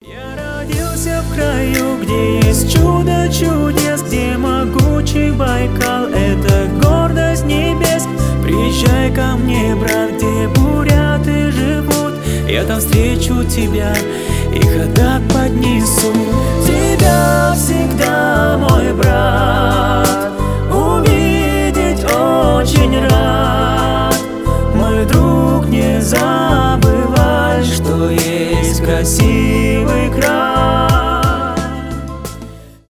поп , душевные